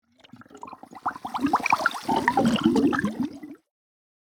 Free Water Sound Effects.
Underwater-movement-with-fizzle.mp3